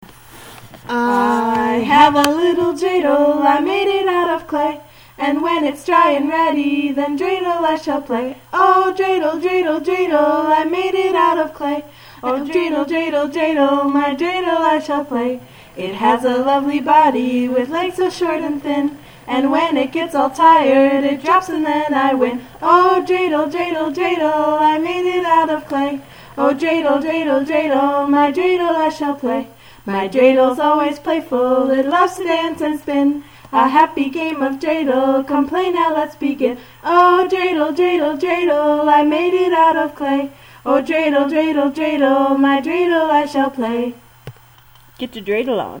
The 2006 Blogger Christmahanukwanzaakah Online Holiday Concert